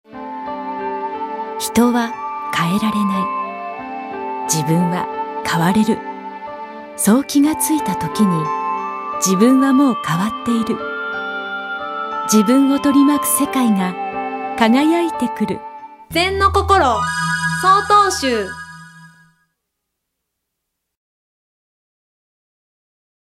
■ラジオCM「自我の気づき」篇（mp3ファイル）